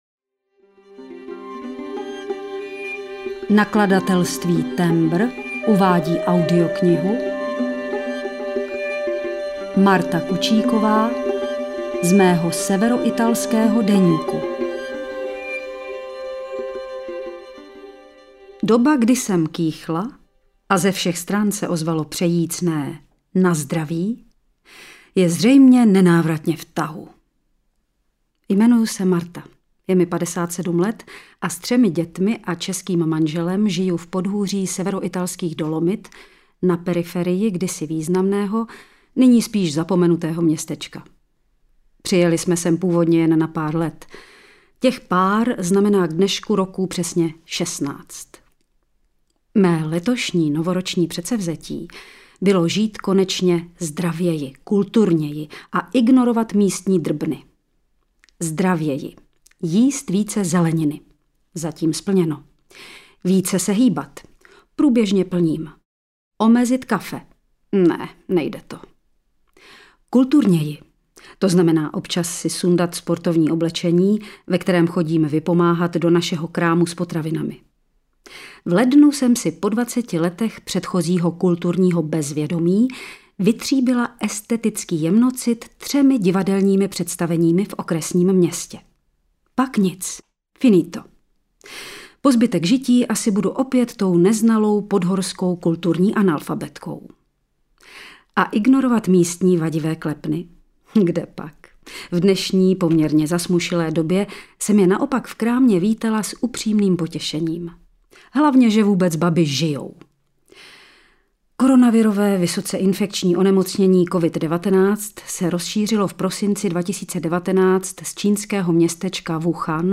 Z mého severoitalského deníku audiokniha
Ukázka z knihy